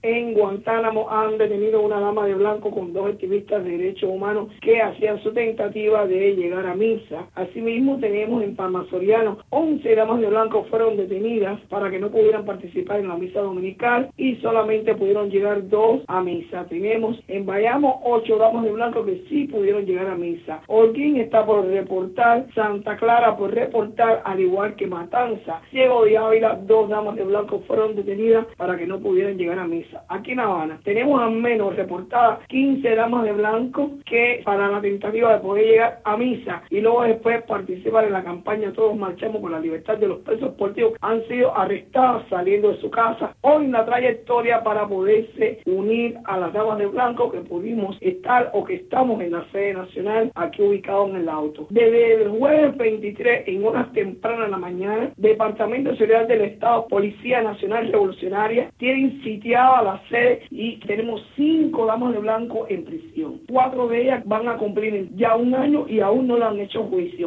Declaraciones de Berta Soler a Radio Martí sobre arrestos el domingo.